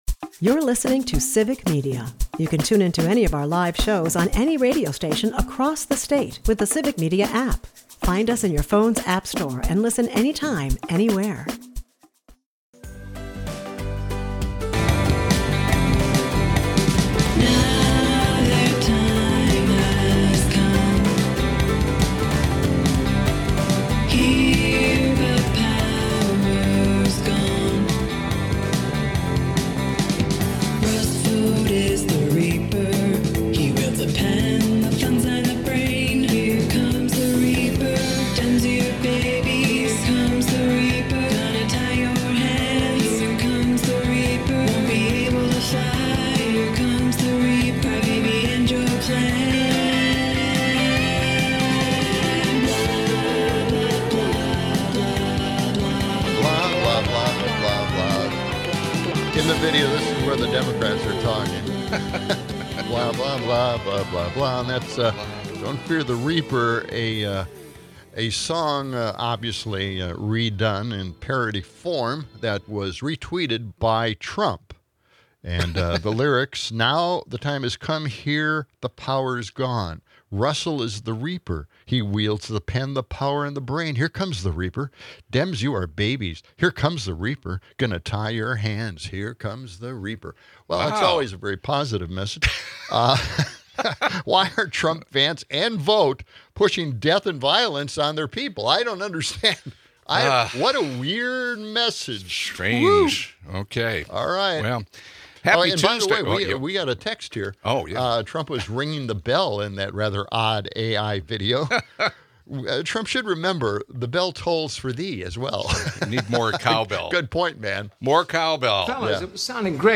The hosts discuss AI videos, Antifa hysteria, and the right-wing backlash against transgender cartoons on Netflix.